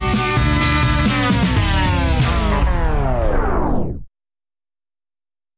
Звук замедленной мелодии